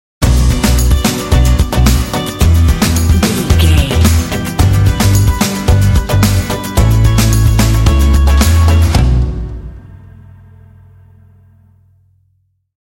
Aeolian/Minor
energetic
dramatic
synthesiser
drums
acoustic guitar
bass guitar
electric guitar
rock
alternative rock
indie